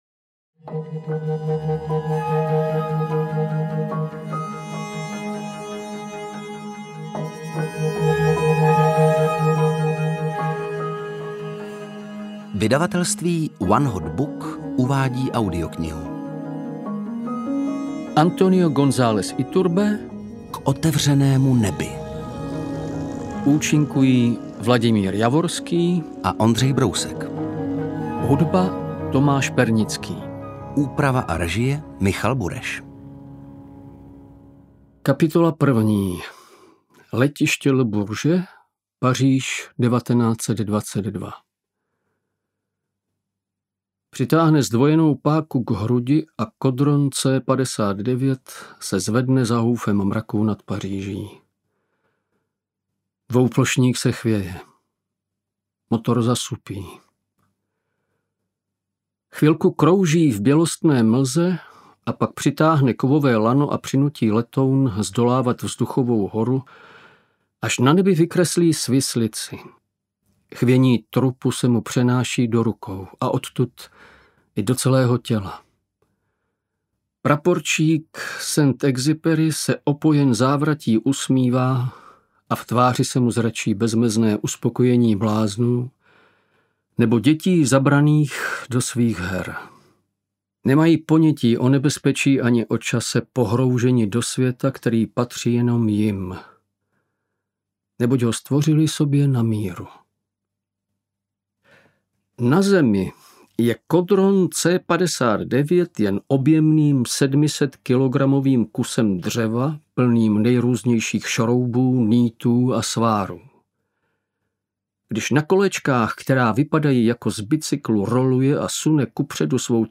Interpreti:  Ondřej Brousek, Vladimír Javorský
AudioKniha ke stažení, 79 x mp3, délka 22 hod. 12 min., velikost 1215,0 MB, česky